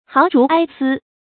豪竹哀丝 háo zhú āi sī
豪竹哀丝发音
成语注音 ㄏㄠˊ ㄓㄨˊ ㄞ ㄙㄧ